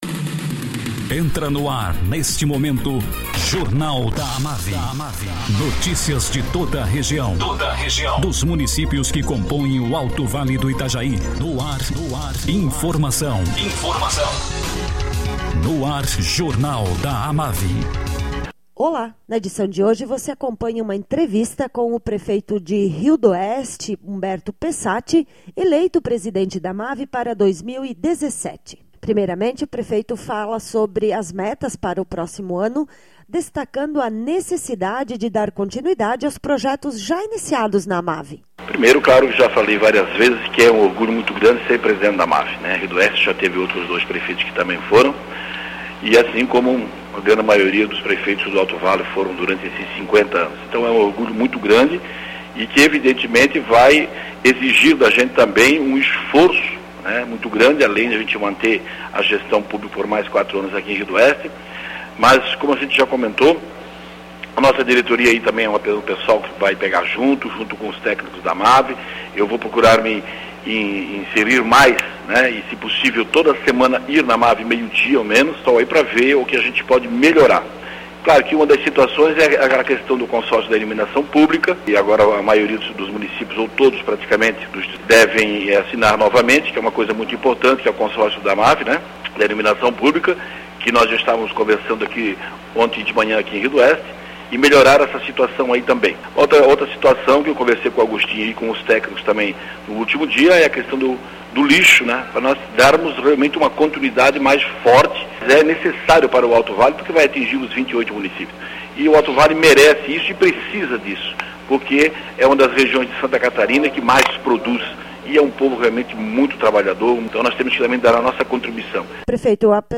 Prefeito de Rio do Oeste e presidente eleito da AMAVI, Humberto Pessatti, fala sobre objetivos a frente da AMAVI em 2017 e avalia seus primeiros 4 anos como prefeito.